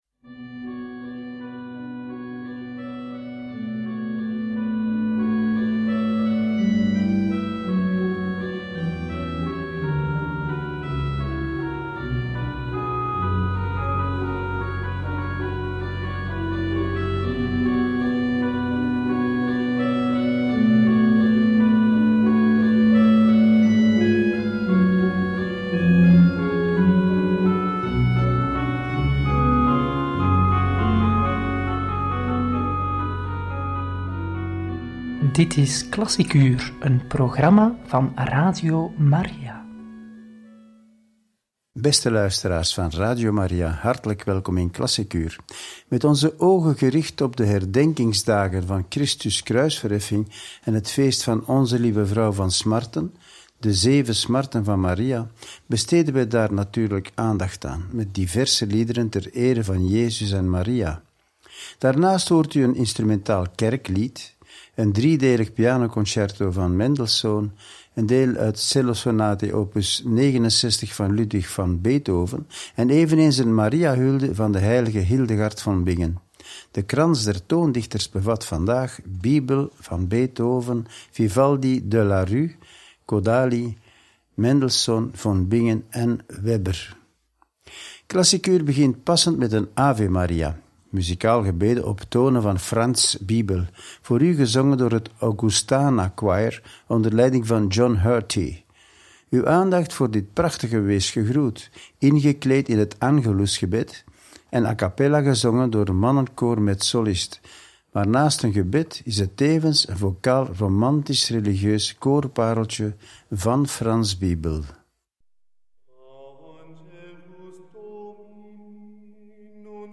Muziek voor het feest van Kruisverheffing, OLV van Smarten en diversen liederen ter ere van Jezus en Maria – Radio Maria